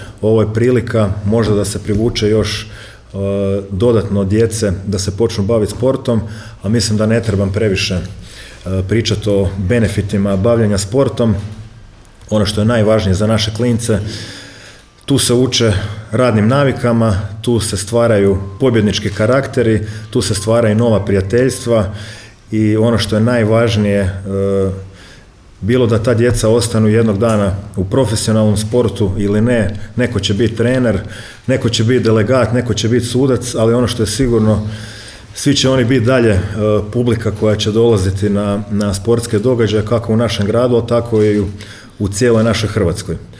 Prednost Sportskih igara, dodaje Orlić je što na njima mogu sudjelovati i sudjeluju i mladi koji se inače ne bave sportom